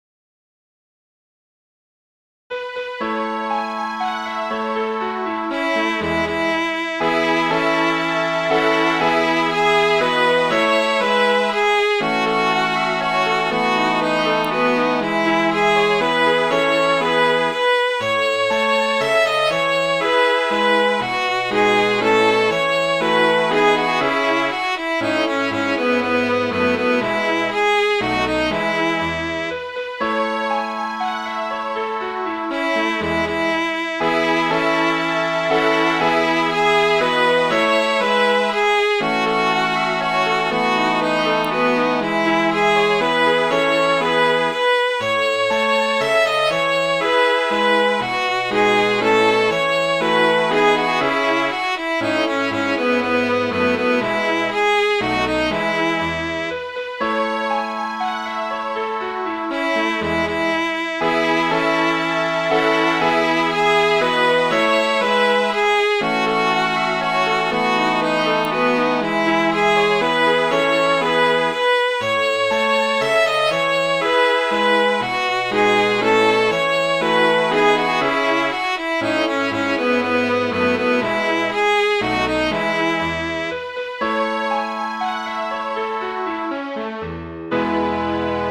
Midi File, Lyrics and Information to Hares on the Mountains